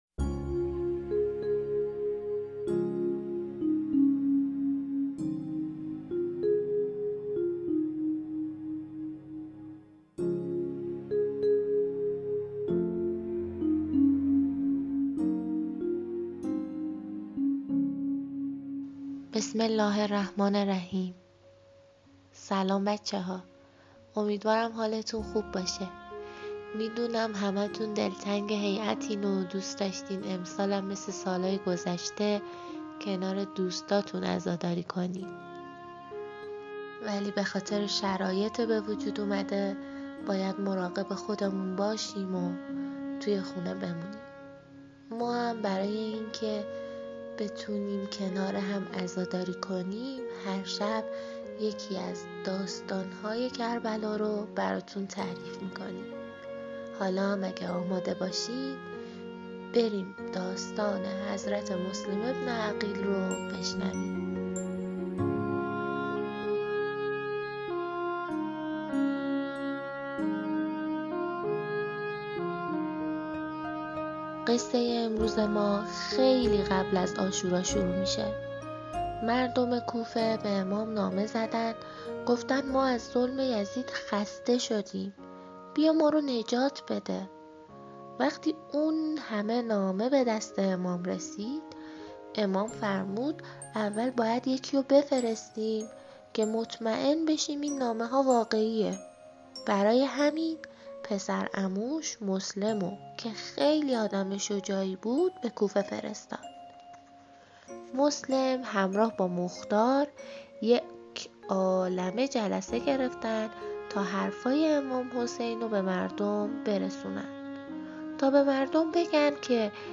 قصه‌های محرم برای بچه‌ها / مسلم بن عقیل